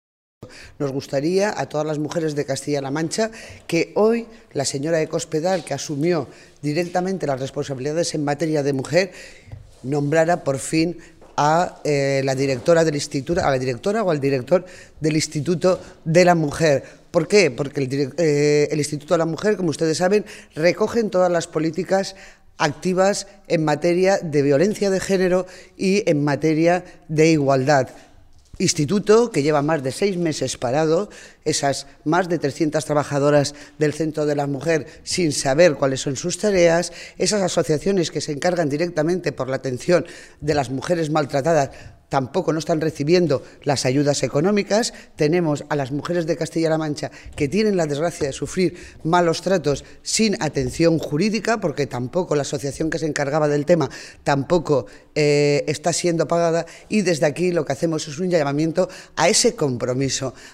Mercedes Giner, portavoz del Área de la Mujer del Grupo Socialista
Cortes de audio de la rueda de prensa